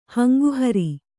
♪ hanguhaṛi